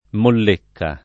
[ moll % kka ]